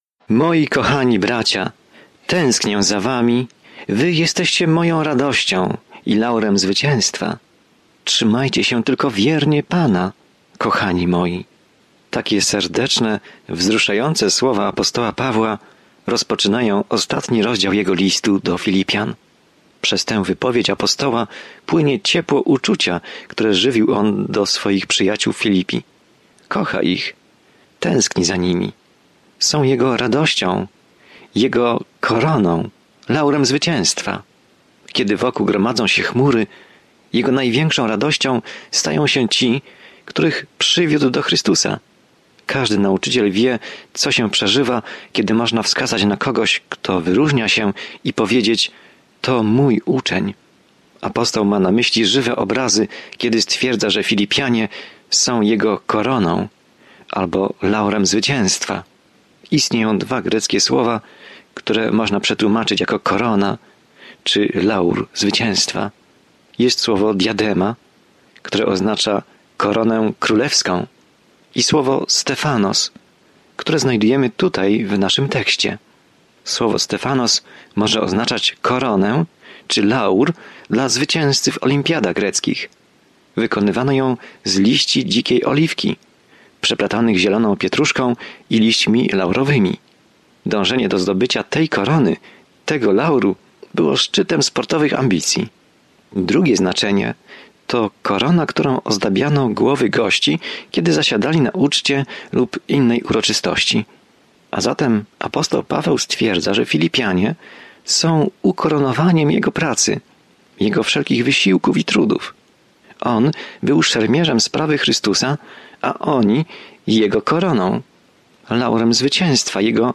Pismo Święte Filipian 4:1-5 Dzień 13 Rozpocznij ten plan Dzień 15 O tym planie To podziękowanie skierowane do Filipian daje im radosną perspektywę na trudne czasy, w których się znajdują, i zachęca ich, aby pokornie przez nie przejść razem. Codziennie podróżuj przez List do Filipian, słuchając studium audio i czytając wybrane wersety słowa Bożego.